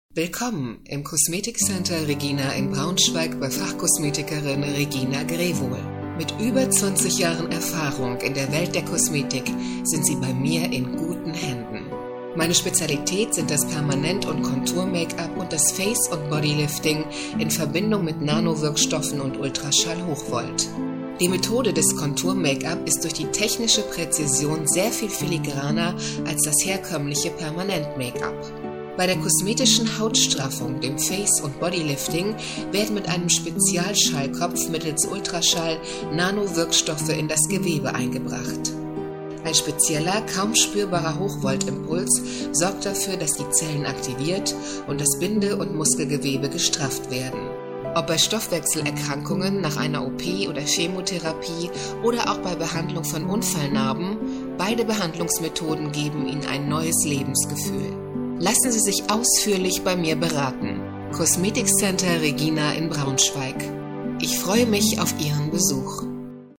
Presentation